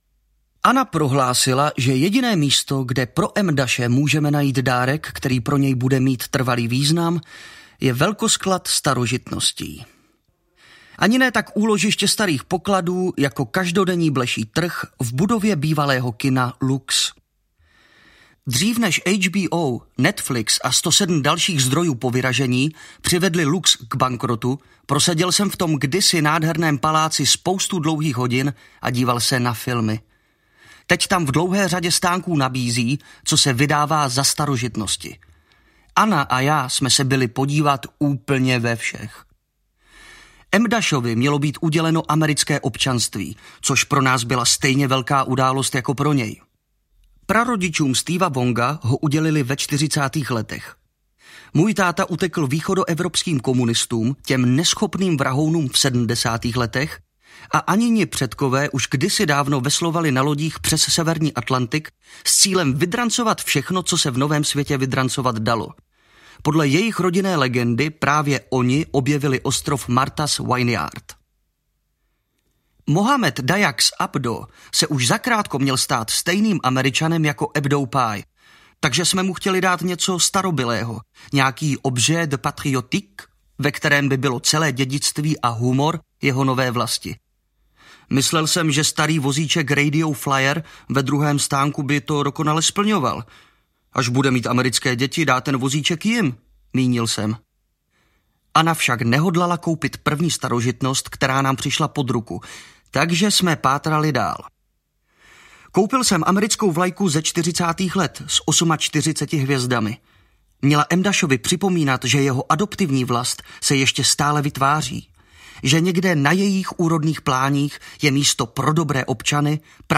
Neobvyklý typ audiokniha
Ukázka z knihy